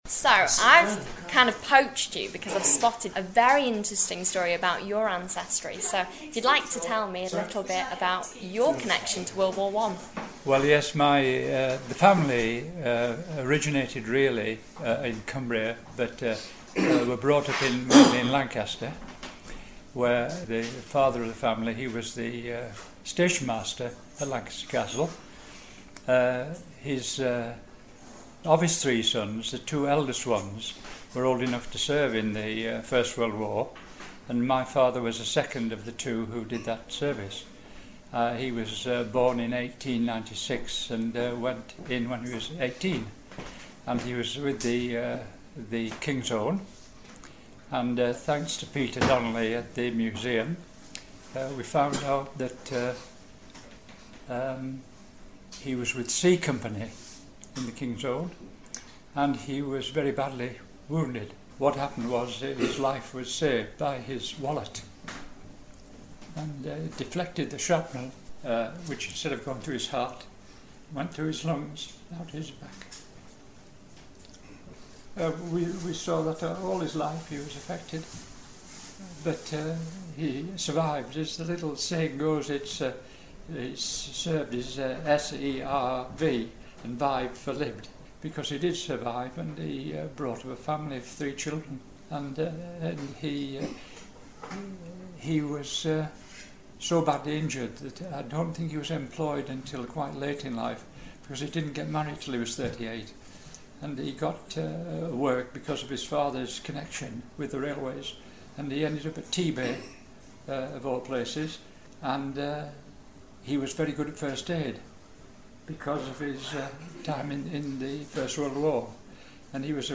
Interview recorded in June 2014.